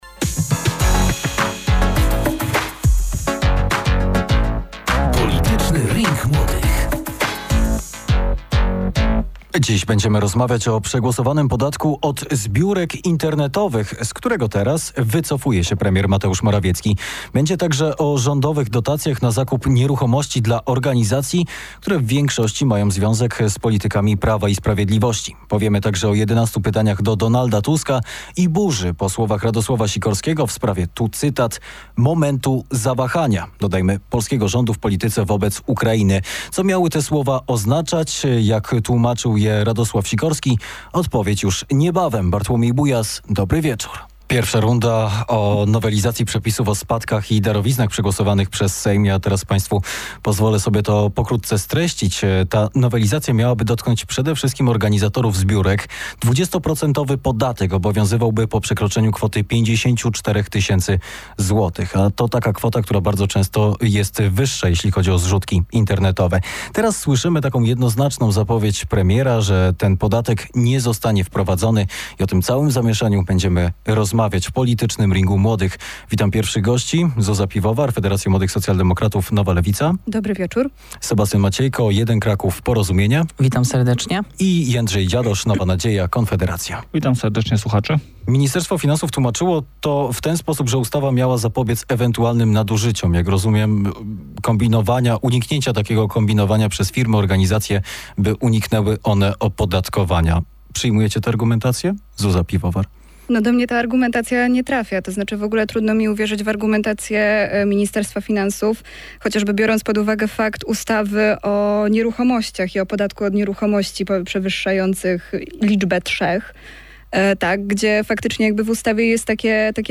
Audycja, w której dochodzi do wymiany i ścierania się poglądów młodych polityków uczestniczących aktywnie w życiu publicznym.
Z głosem Dartha Vadera będą musieli się Państwo trochę przemęczyć!